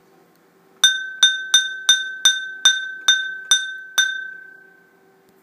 Rooster Garden Bell
A cast iron classic black rooster bell.
The bell is a good size, the bracket is simple and shows off the rooster as the main decorative feature.